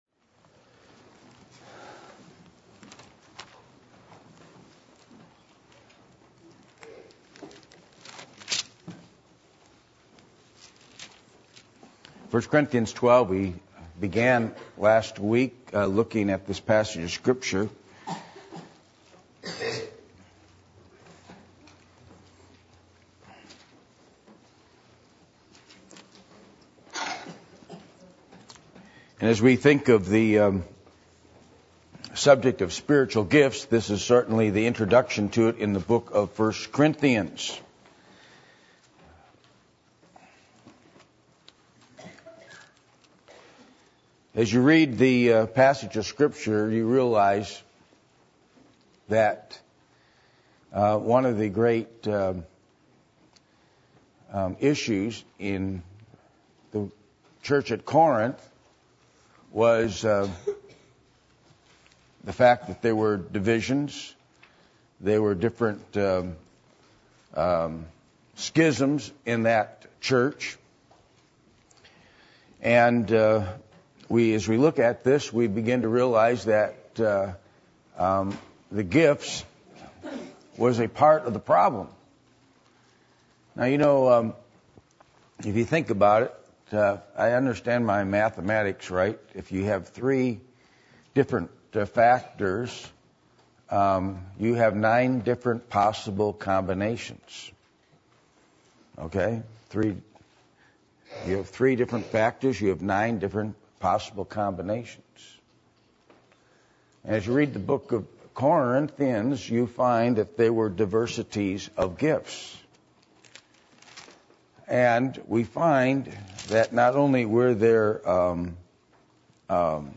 Passage: 1 Corinthians 12:1-13 Service Type: Sunday Evening